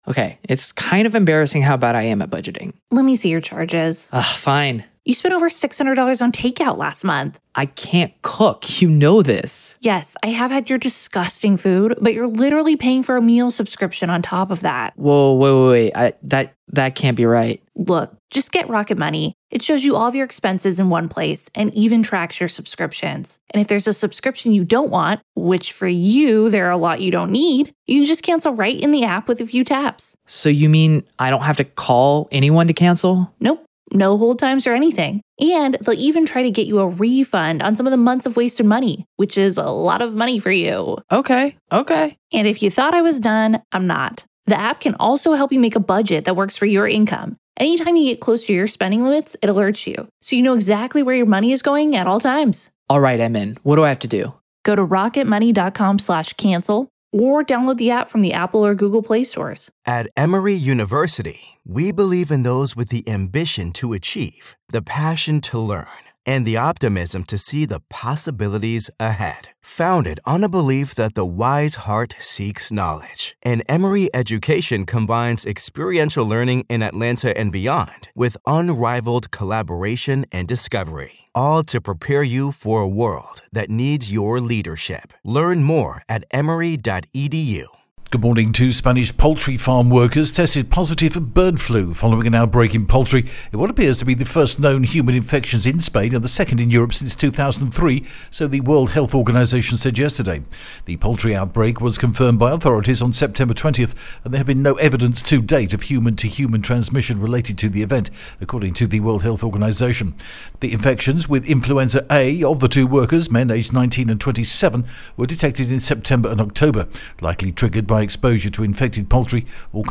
The latest Spanish news headlines in English: 4th November 2022